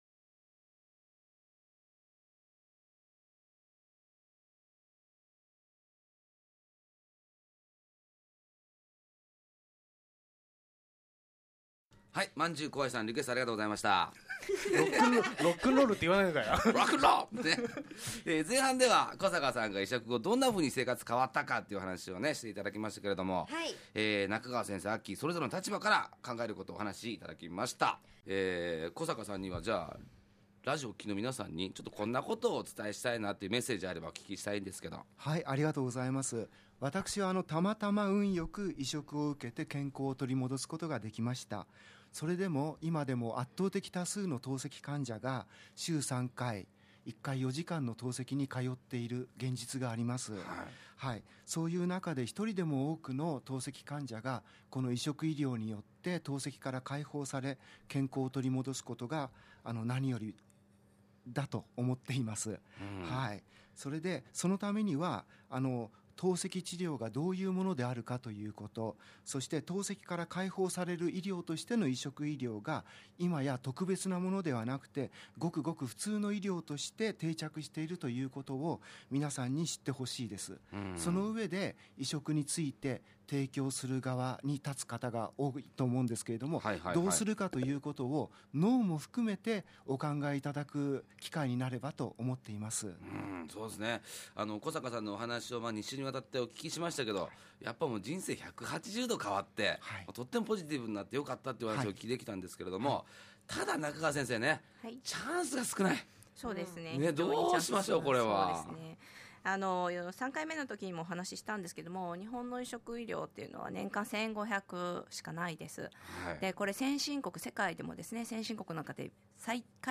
※BGMやリクエスト曲、CMはカットしています。